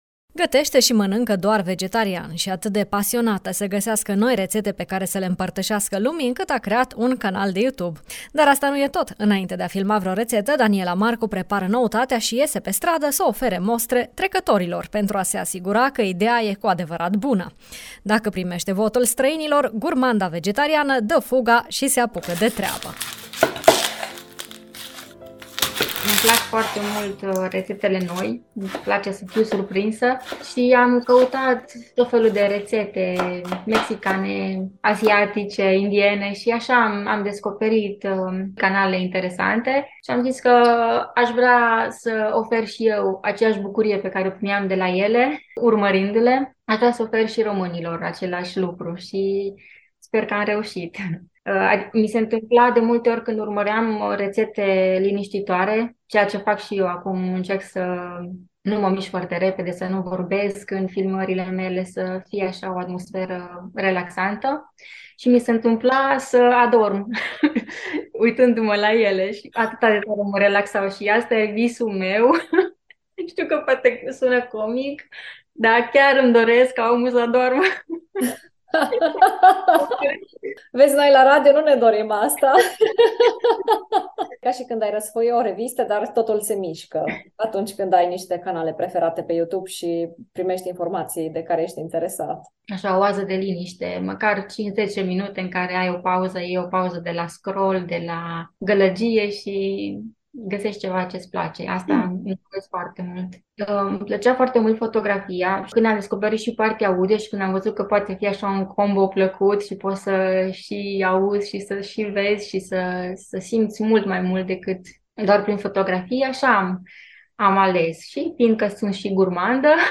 4-apr-BDF-CruF-cu-sunete.mp3